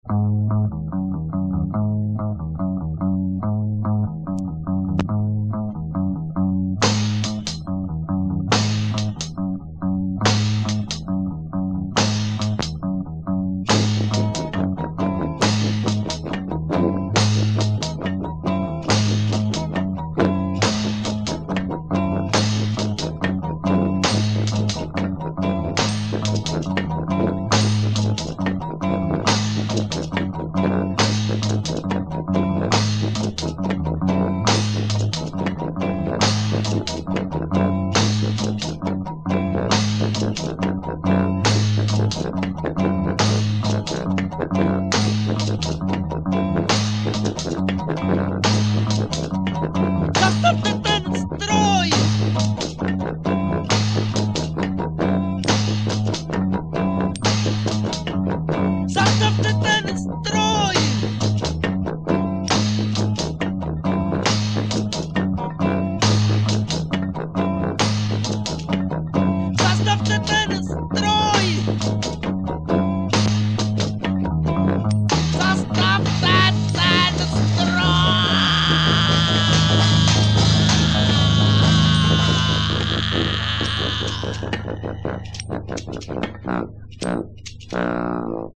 voc, g
fl, voc